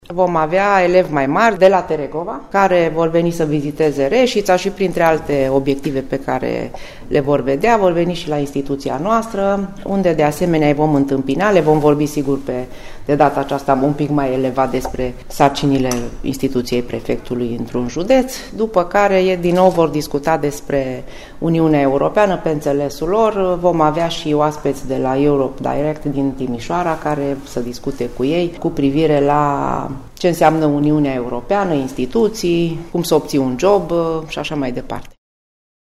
O manifestare similară va avea loc şi miercuri, 20 aprilie, spune prefectul Florenţa Albu: